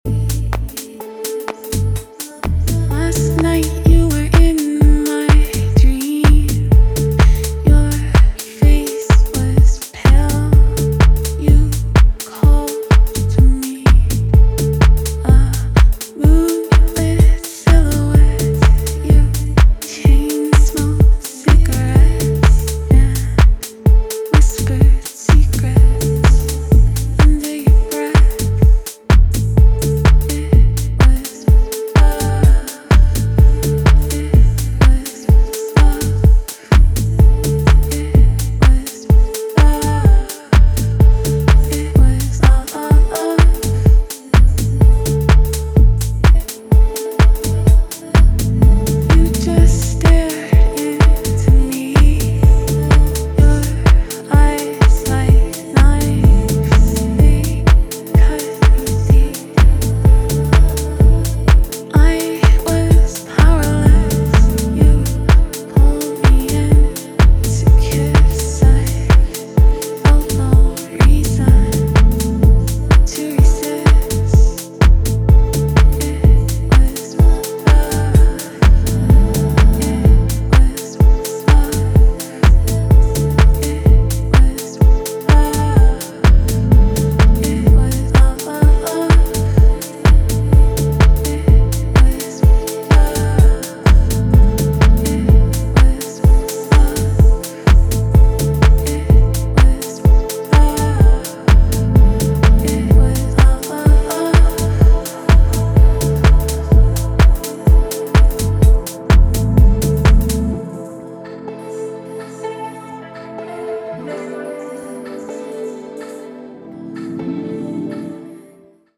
歌詞も含めて官能的なヴォーカルとベースラインの調和をシルキーなシンセが包むA面